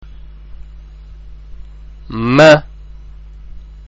ഉച്ചാരണം (പ്ലേ ബട്ടണ്‍ അമര്‍ത്തുക)
024_Meem[1].mp3